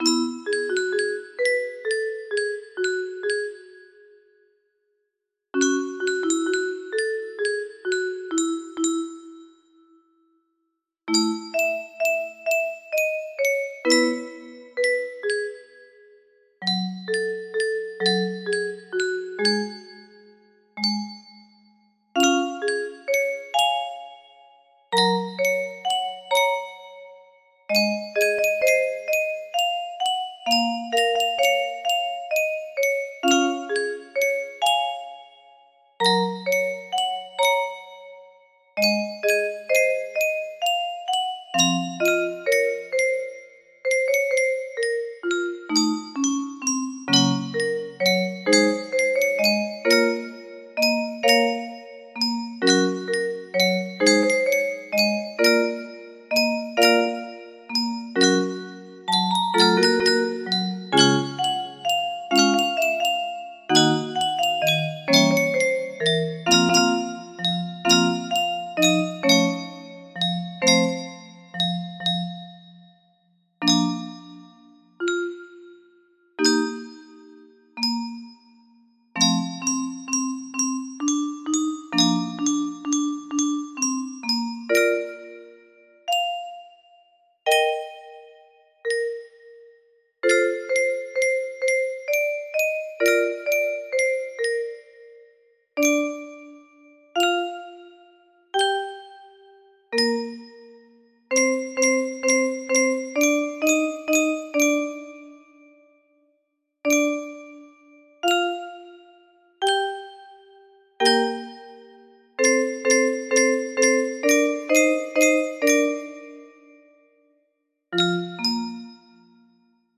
Clone of Unknown Artist - Untitled music box melody
Full range 60